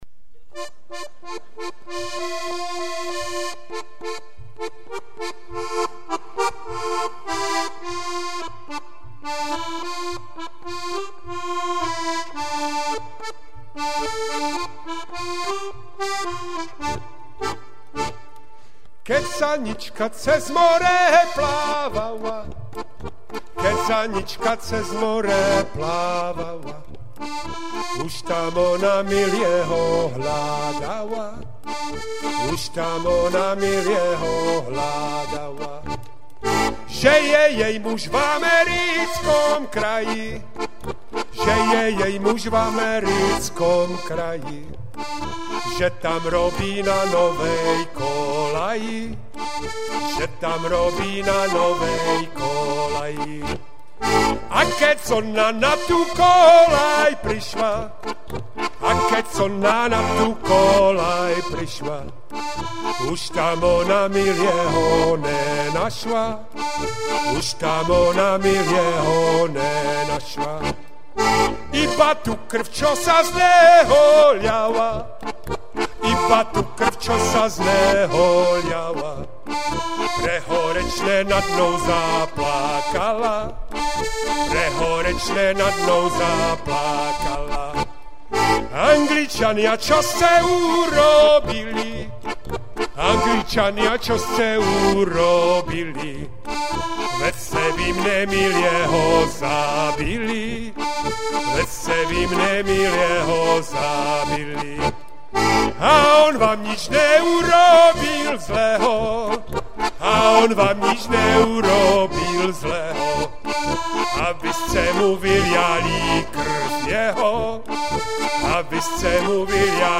Lidové písně zpívané | Jarek Nohavica